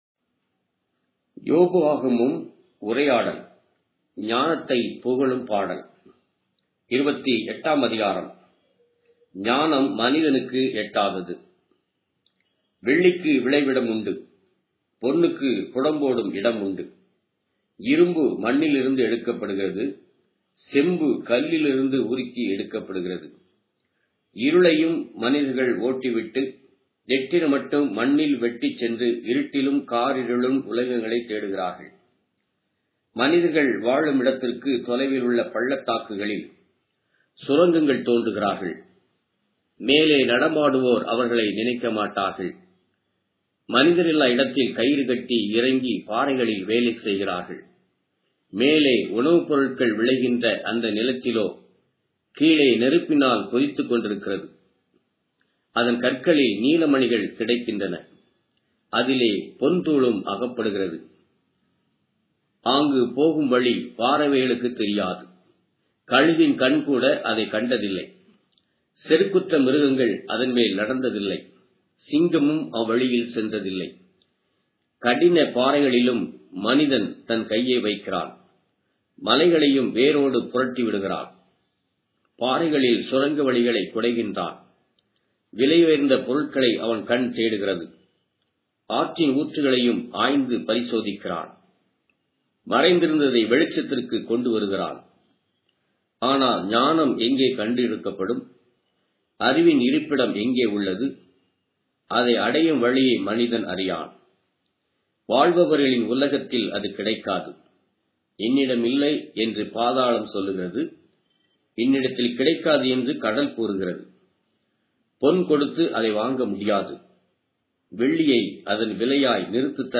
Tamil Audio Bible - Job 28 in Rcta bible version